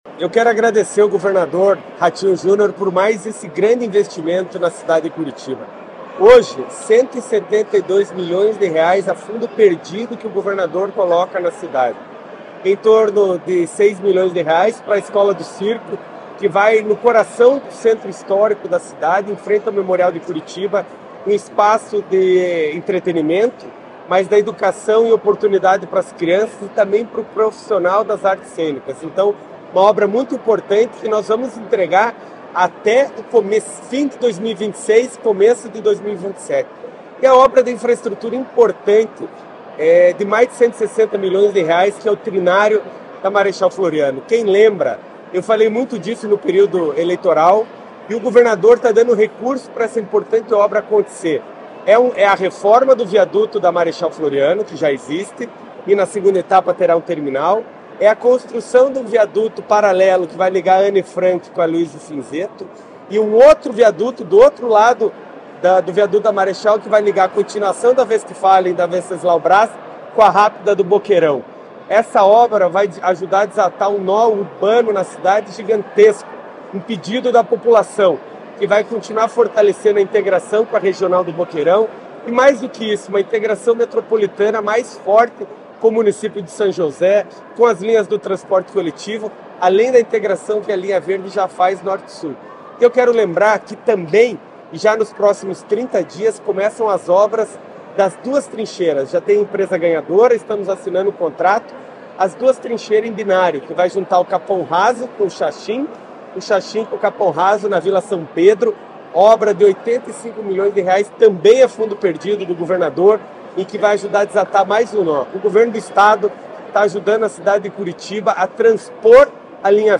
Sonora do prefeito de Curitiba, Eduardo Pimentel, sobre os investimentos feitos pelo governo estadual na Capital paranaense